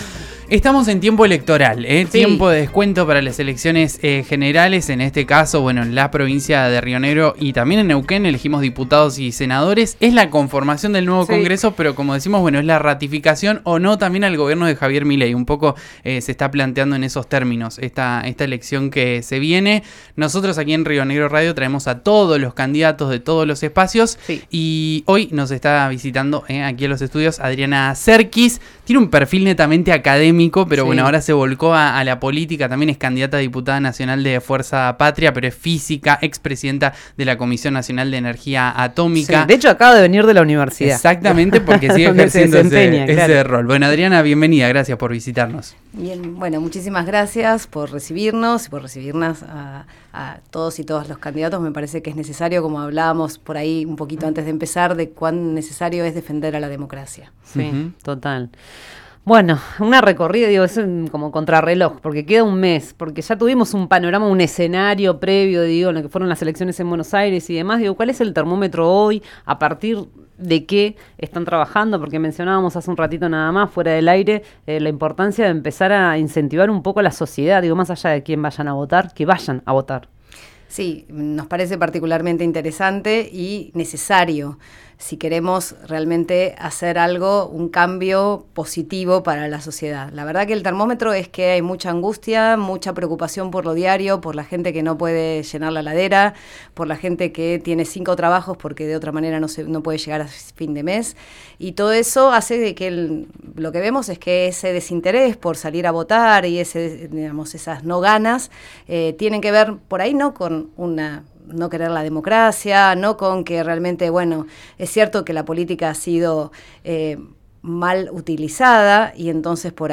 La candidata a diputada nacional por Fuerza Patria sostuvo que son la única fórmula que puede 'frenar' a Javier Milei. Afirmó que la baja de la inflación es una 'ilusión' y cuestionó la política económica del gobierno de La Libertad Avanza. Escuchá la entrevista completa.
La candidata a diputada nacional de Fuerza Patria, Adriana Serquis, dialogó con RN Radio.